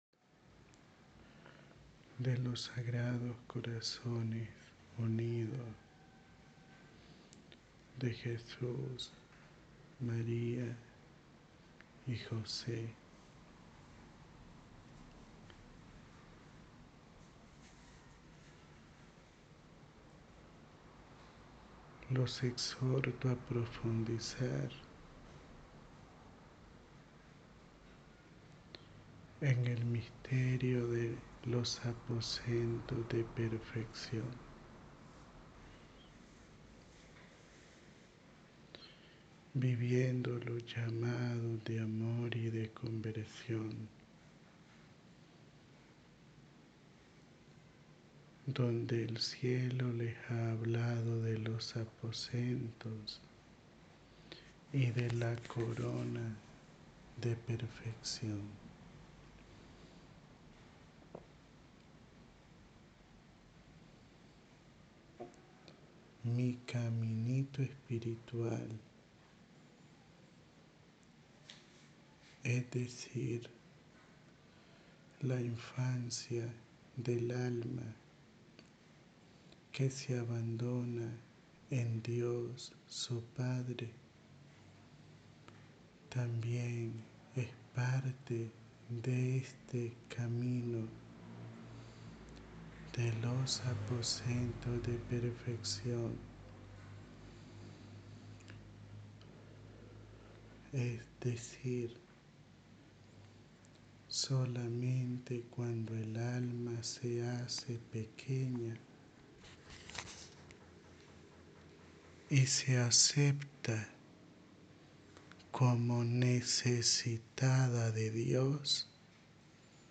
Áudio da Mensagem